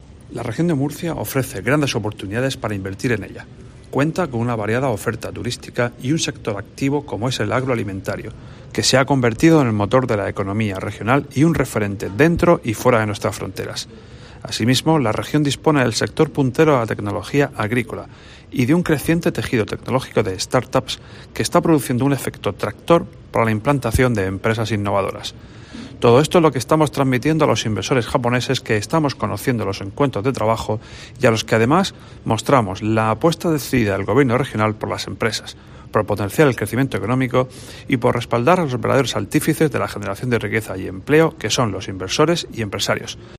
Luis Alberto Marín, consejero de Economía, Hacienda y Empresa